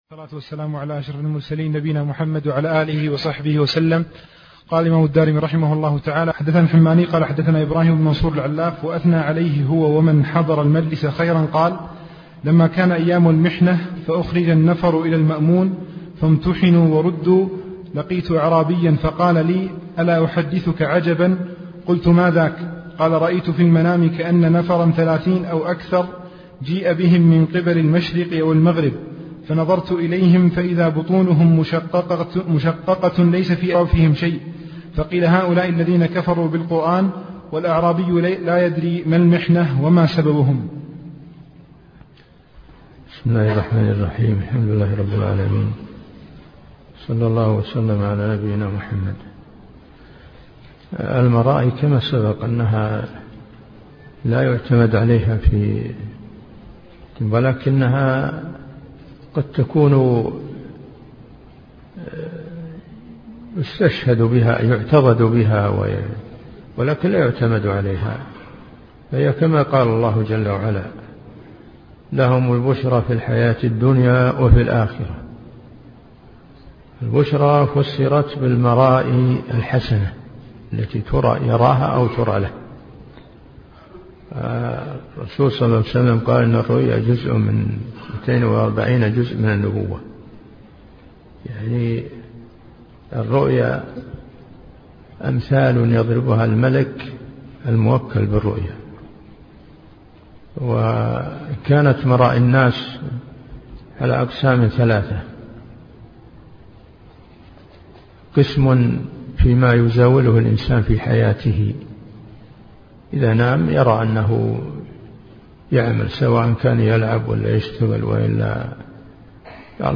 عنوان المادة الدرس (10) شرح رسالة في الصفات تاريخ التحميل الخميس 9 فبراير 2023 مـ حجم المادة 20.84 ميجا بايت عدد الزيارات 278 زيارة عدد مرات الحفظ 132 مرة إستماع المادة حفظ المادة اضف تعليقك أرسل لصديق